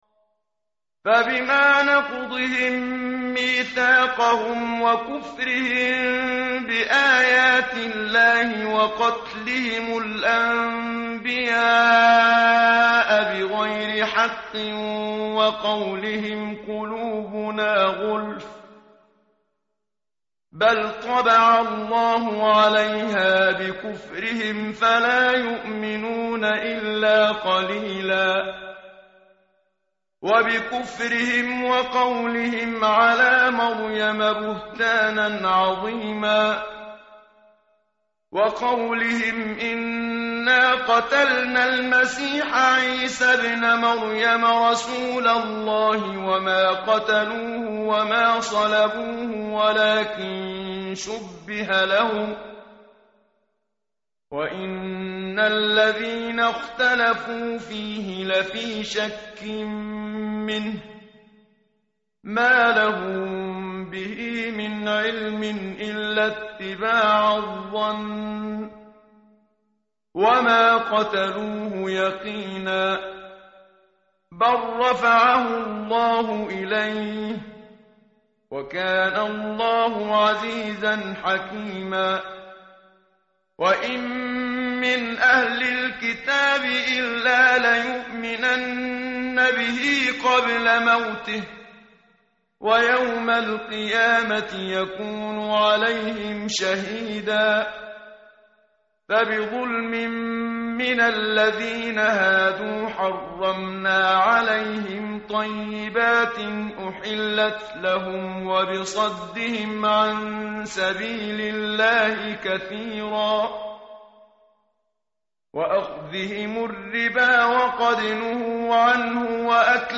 tartil_menshavi_page_103.mp3